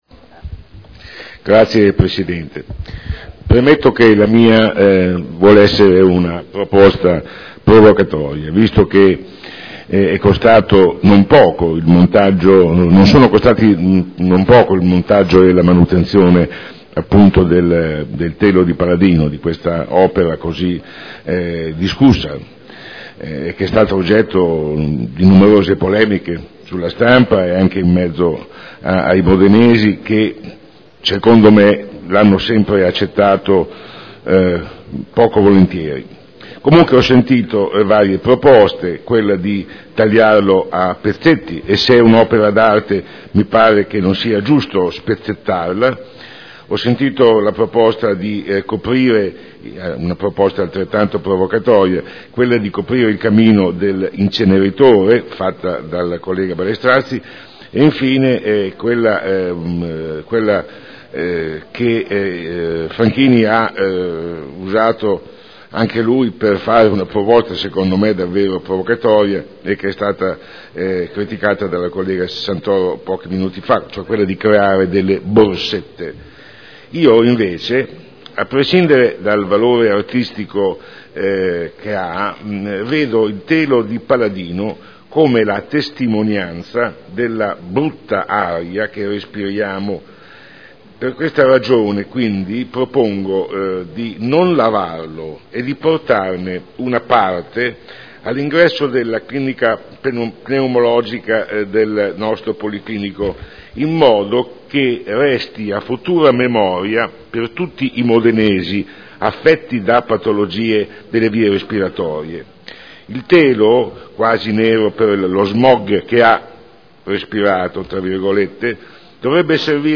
Dibattito